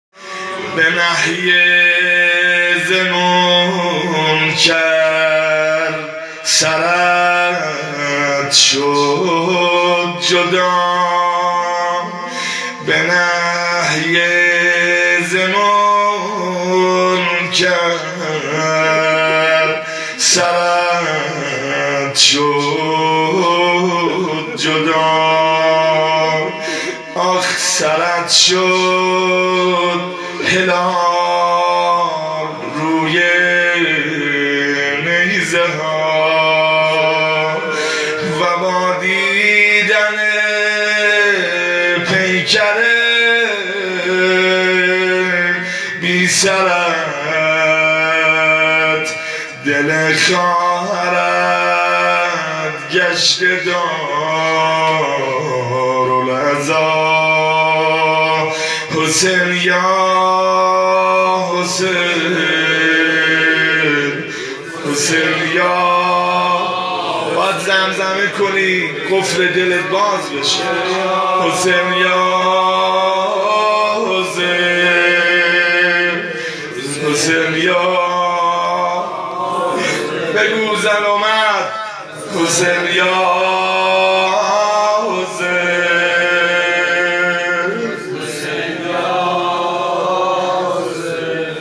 روضه قمربنی هاشم(ع)-جلسه هفتگی22اردیبهشت97
جلسه هفتگی 22اردیبهشت97